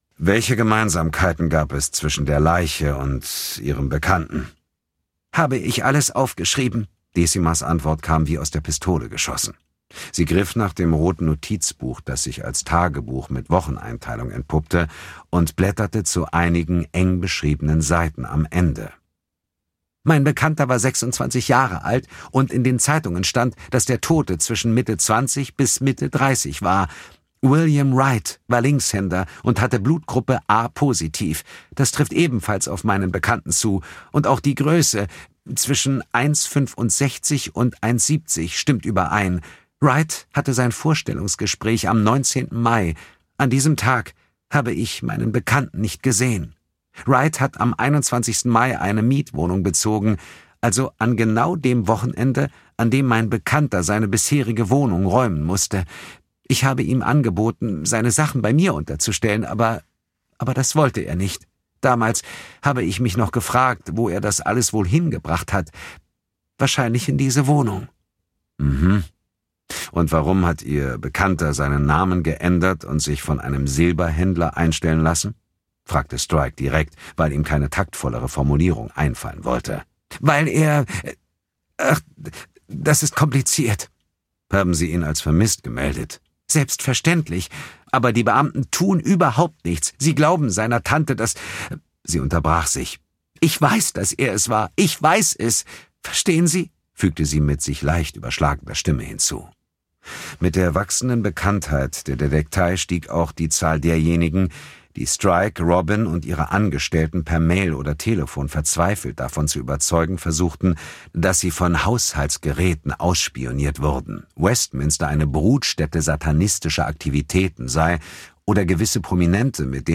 Der Tote mit dem Silberzeichen (DE) audiokniha
Ukázka z knihy
• InterpretDietmar Wunder